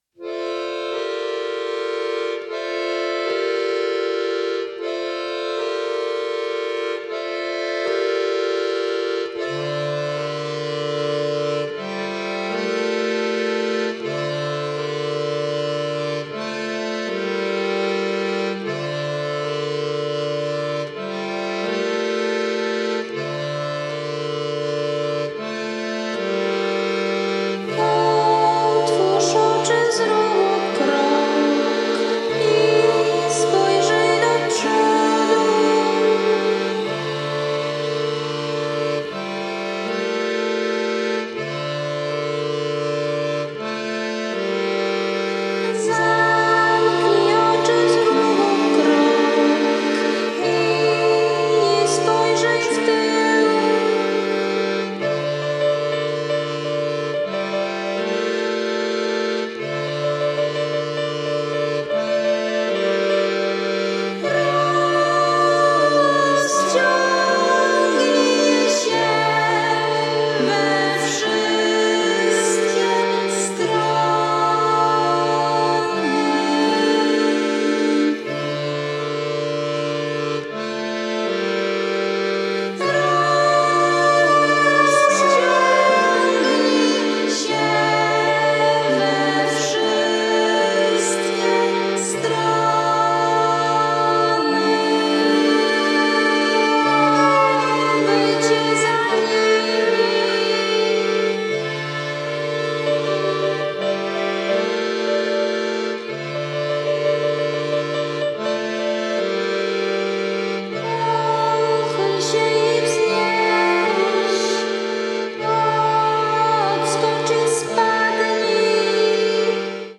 東欧的で中世的！スラブ音楽、アンビエント、ミニマリズムに神聖な歌唱が組み合わった極上の1枚！